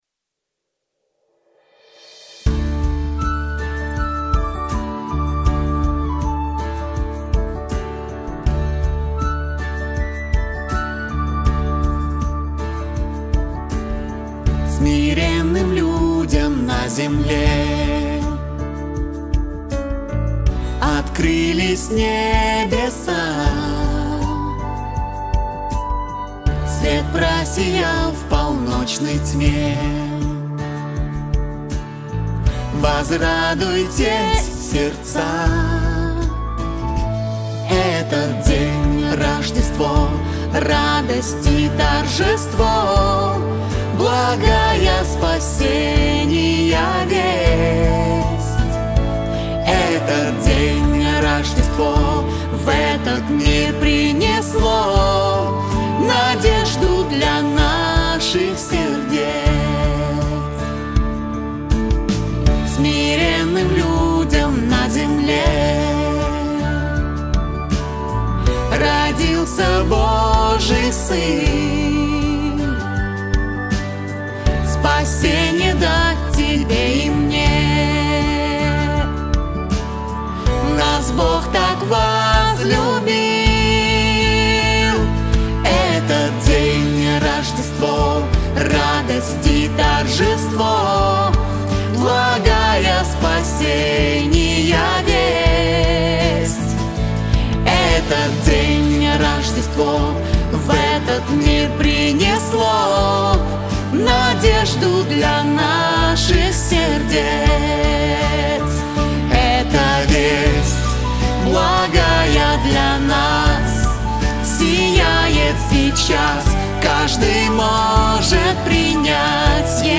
303 просмотра 777 прослушиваний 40 скачиваний BPM: 80